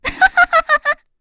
FreyB-Vox_Happy3.wav